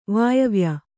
♪ vāyavya